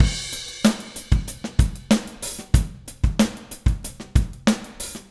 Trem Trance Drums 02 Crash.wav